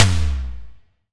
Index of /90_sSampleCDs/EdgeSounds - Drum Mashines VOL-1/SIMMONSDRUMS